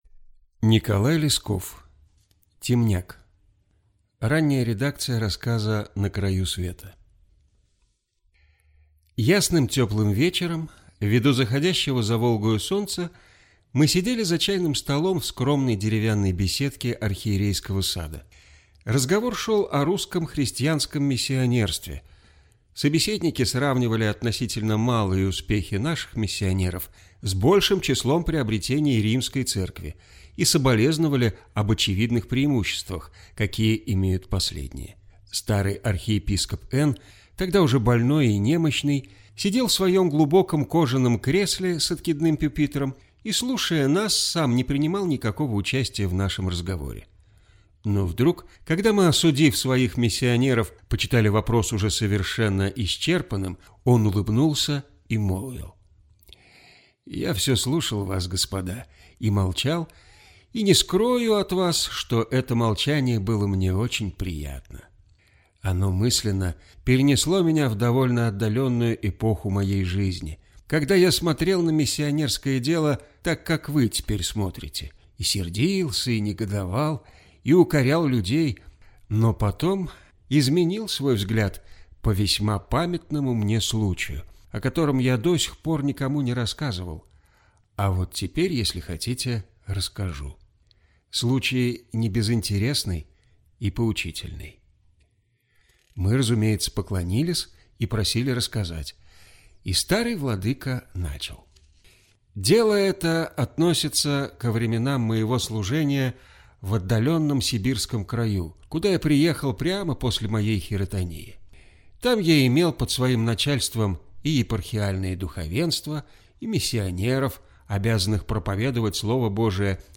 Аудиокнига Темняк | Библиотека аудиокниг